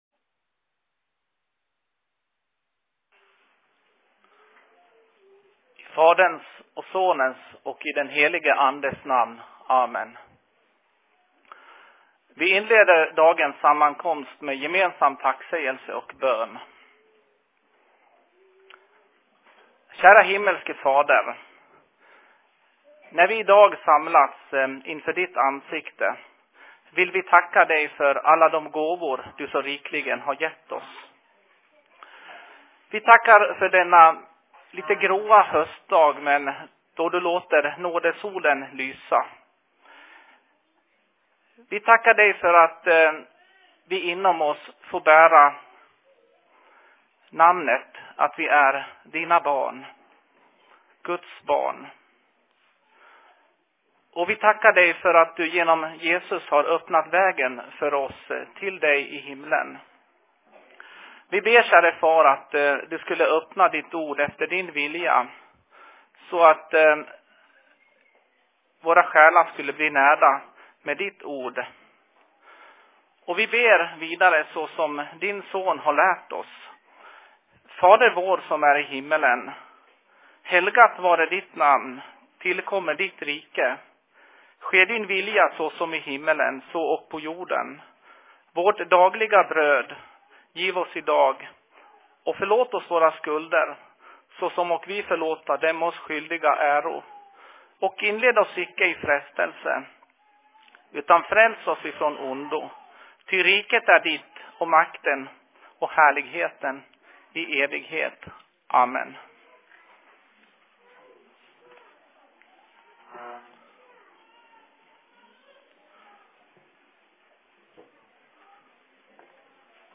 Se Seurapuhe Taalainmaan RY:llä 16.11.2014
Paikka: SFC Dalarna
Simultaanitulkattu Ruotsi, Suomi